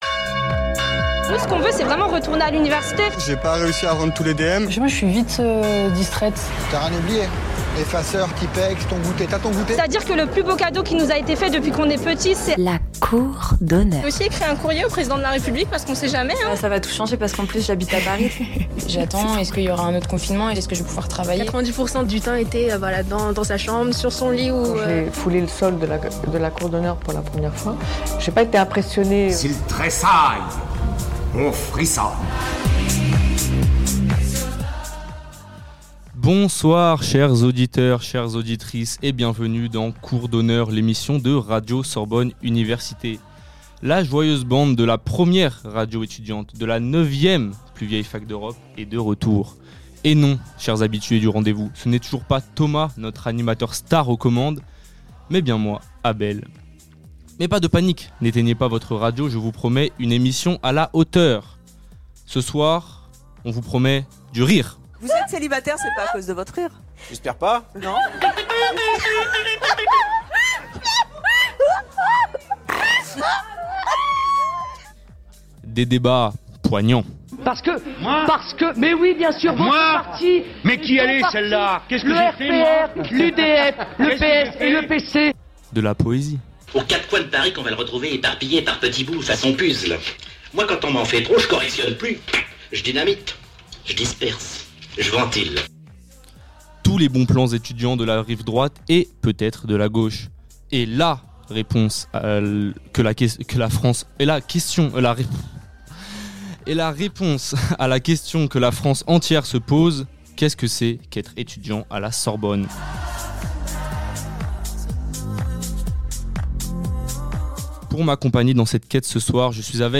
La bande de Cour d'Honneur répond à cette question : C'est quoi être étudiant à Sorbonne Université ?La vie étudiante à la Sorbonne sous toutes ses facettes. Anecdotes sur la plus vieille fac de France, interview d'enseignants, reportages sur le quotidien des étudiants, carte postale sonore des lieux emblématique de l'université
La bande de Cour d'Honneur en plein direct